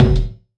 Kik Dirtstak 02.wav